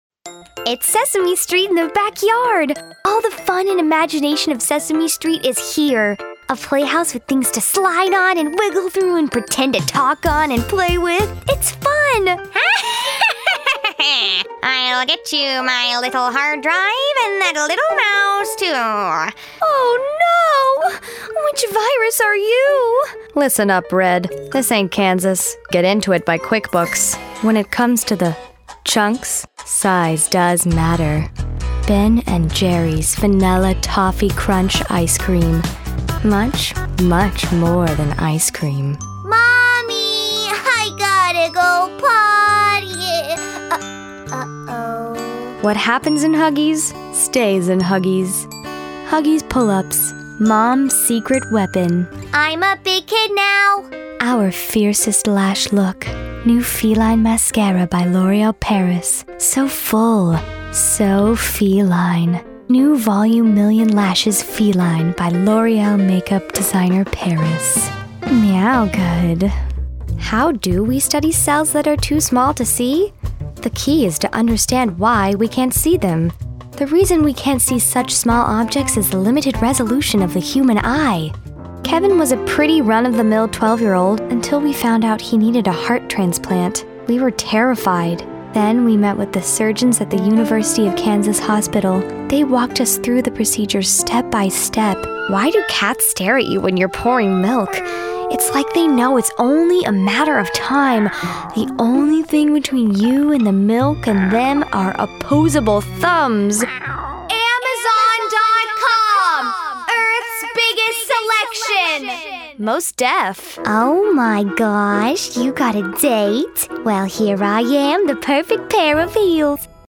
Two minute voiceover demo